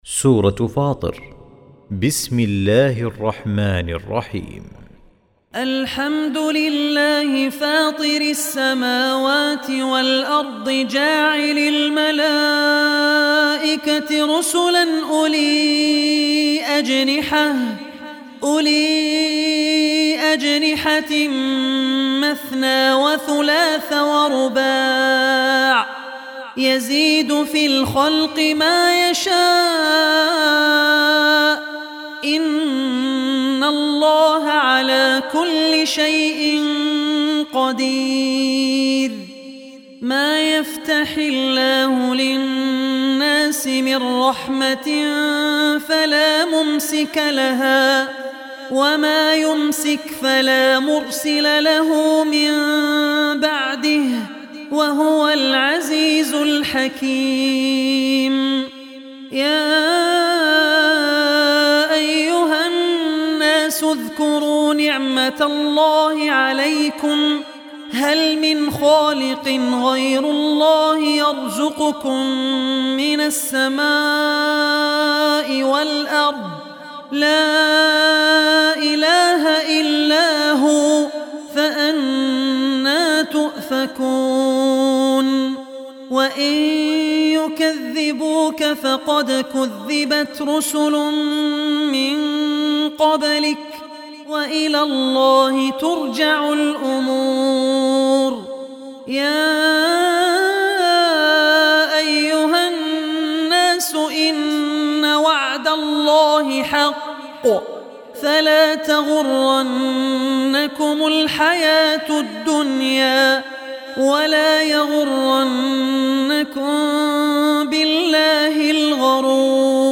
Surah Fatir Recitation by Abdur Rehman Al Ossi
Surah Fatir, listen online mp3 tilawat / recitation in the voice of Abdur Rehman Al Ossi.